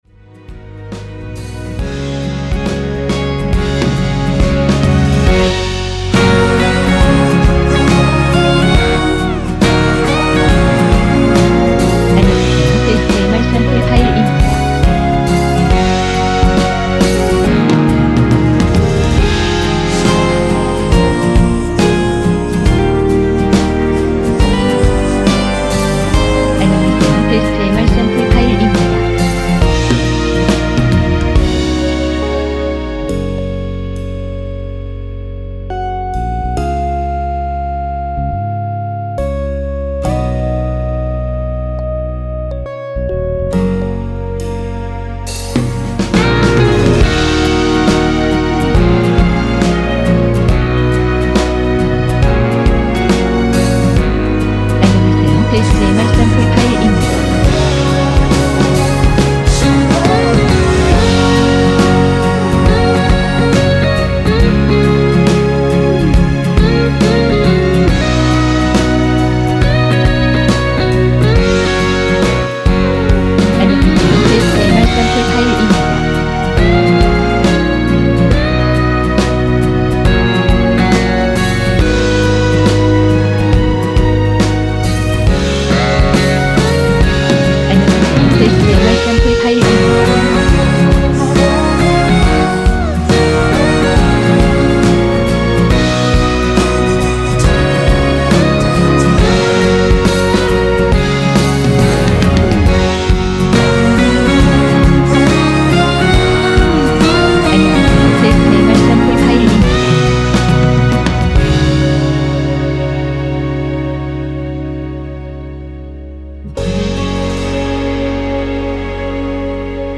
전주 없이 시작하는 곡이라 전주 1마디 만들어 놓았습니다.(일반 MR 미리듣기 참조)
미리듣기에 나오는 부분이 코러스 추가된 부분 입니다.(미리듣기 샘플 참조)
원키에서(-7)내린 코러스 포함된 MR입니다.
Eb
앞부분30초, 뒷부분30초씩 편집해서 올려 드리고 있습니다.
중간에 음이 끈어지고 다시 나오는 이유는